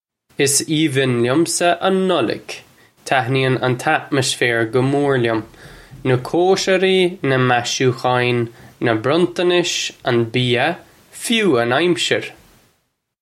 Pronunciation for how to say
Iss eevin lyumsa un Nullug. Tatnee-un un tatmish-fare guh more lyum - nuh kosher-ee, nuh mashookhaw-in, nuh bruntunish, un bee-uh, fyoo un amsher!